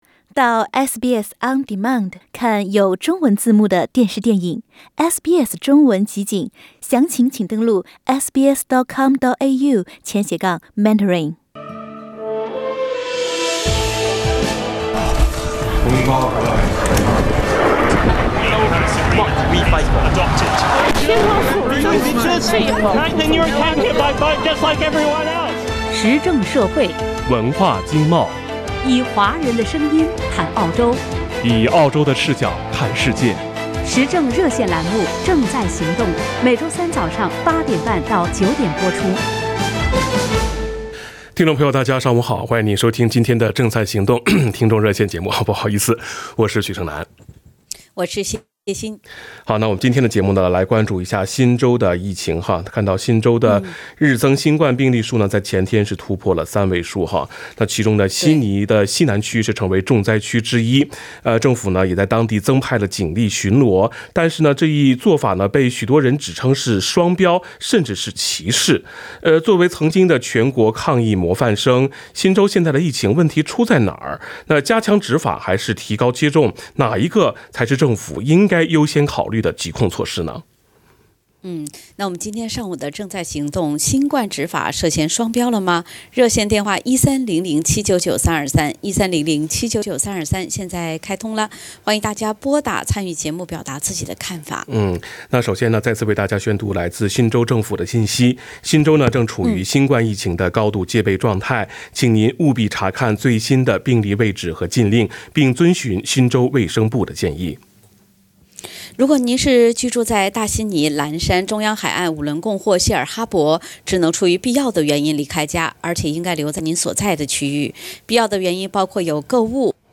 （點擊圖片，收聽熱線回放）